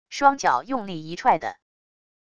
双脚用力一踹地wav音频